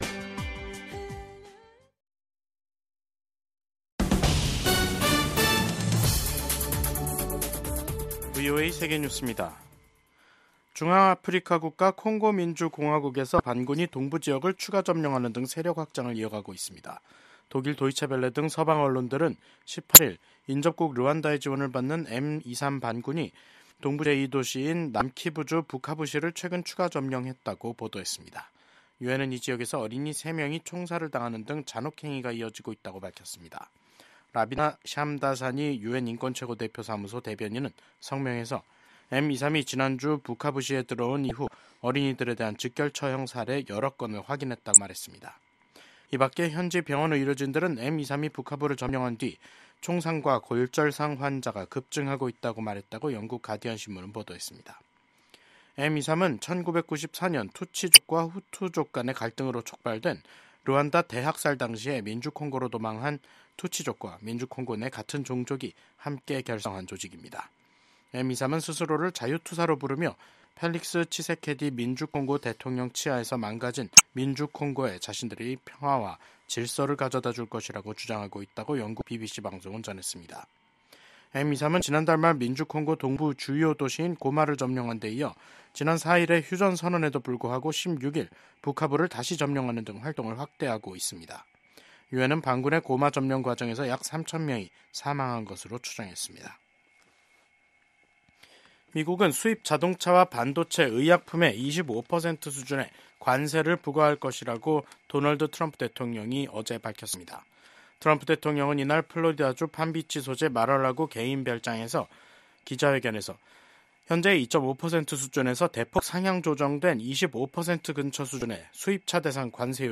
VOA 한국어 간판 뉴스 프로그램 '뉴스 투데이', 2025년 2월 19일 3부 방송입니다. 도널드 트럼프 미국 대통령이 처음으로 러시아에 파병된 북한군의 전사 사실을 처음으로 언급한 가운데, 지난달 우크라이나 군이 생포한 북한 군 1명이 한국으로의 망명 의사를 밝혔습니다. 한국의 문재인 전임 정부 시절 외교안보 라인 고위 인사들이 망명 의사를 밝혔던 북한 선원 2명을 강제송환한 이른바 ‘탈북 어민 강제북송’ 사건을 놓고 1심에서 선고를 유예받았습니다.